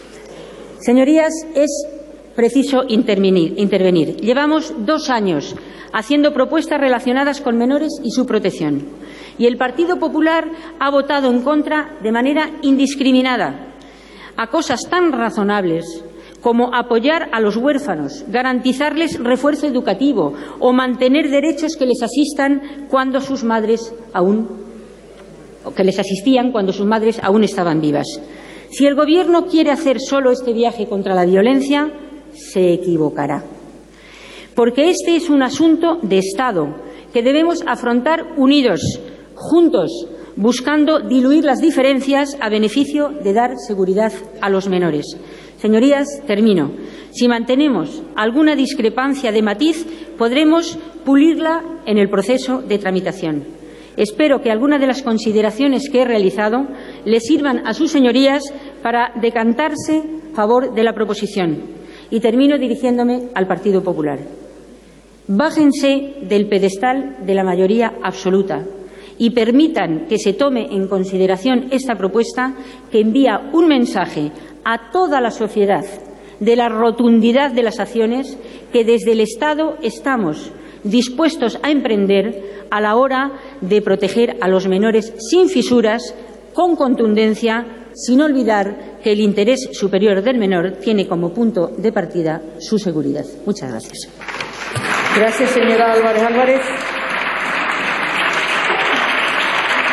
Fragmento de la intervención de Ángeles Alvarez en el pleno defendiendo una proposición de ley para mejorar la protección de los menores ante los casos de violencia de género 8/10/2013